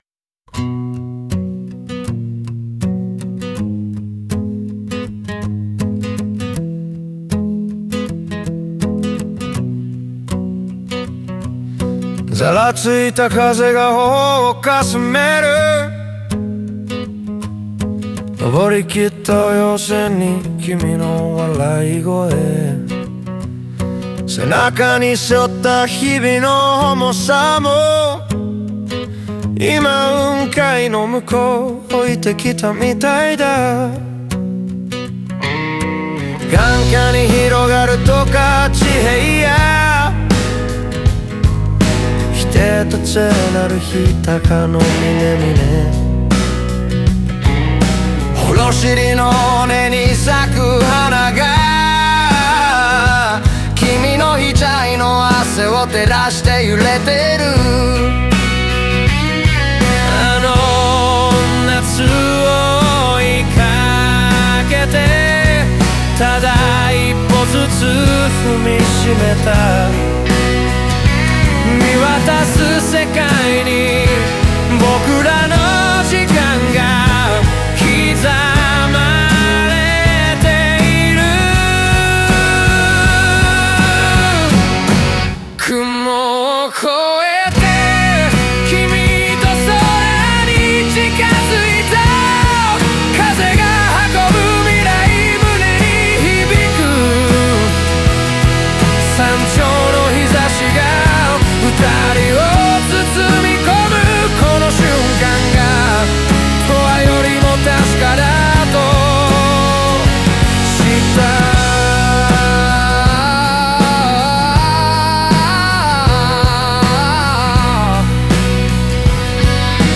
歌詞全体はカントリーロックの力強いリズムに乗せて、挑戦や迷いを乗り越えた先にある約束の地としての山頂を象徴。